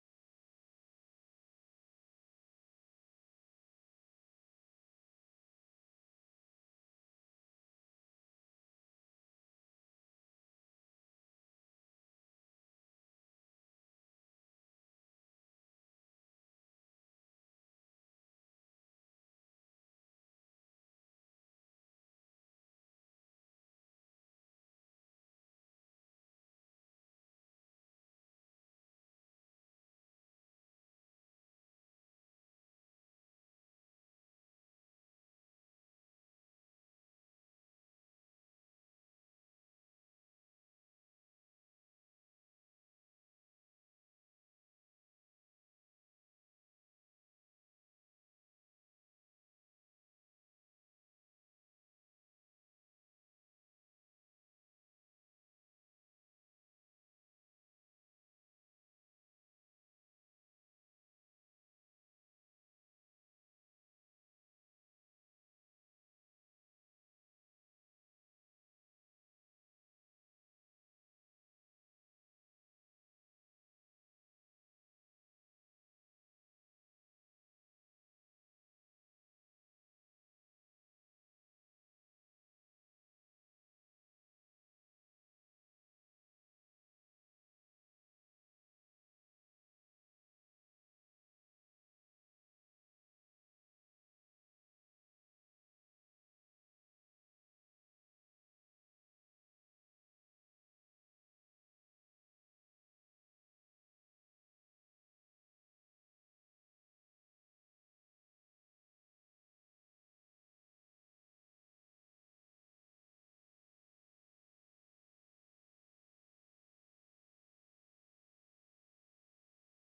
Unfortunately this service had some connection issues, parts are a missing a bit of time.